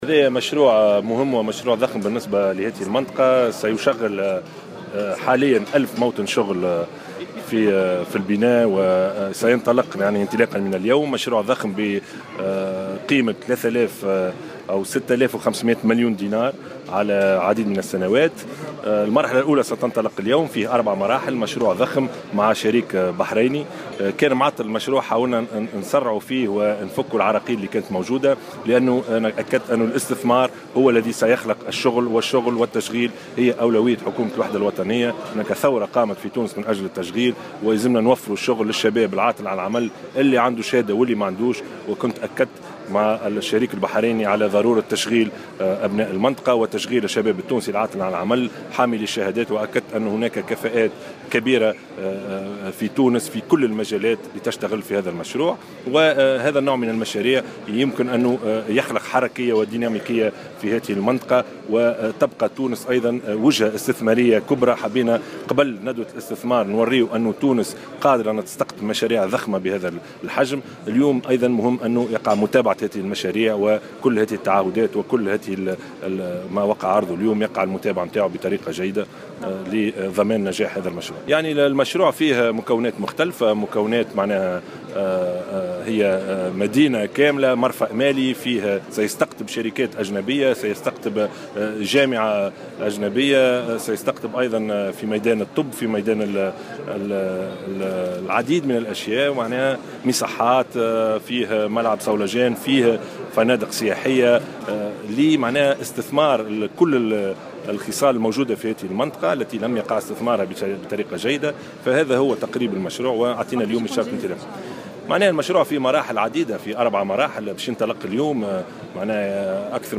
واكد الشاهد، فى تصريح لمراسل الجوهرة أف أم بالمناسبة، أهمية هذا المشروع الضخم على مستوى التشغيل، حيث سيوفر في مرحلة أولى خلال مرحلة الإنجاز ألف موطن شغل، مشددا على أولوية التشغيل بالنسبة لحكومته التي سعت إلى تذليل العراقيل التي أدت إلى تعطيل إنجازه.